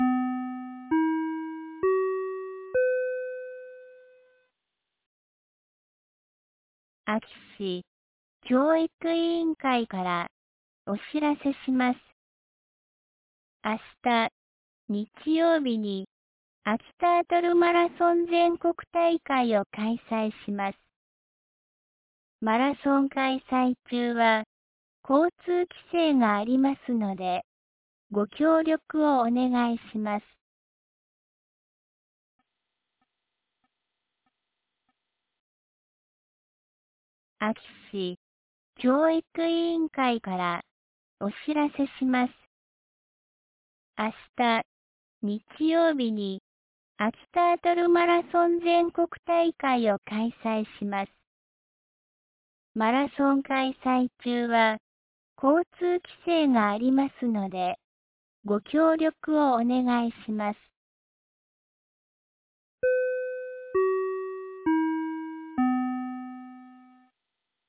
2024年12月07日 16時31分に、安芸市より全地区へ放送がありました。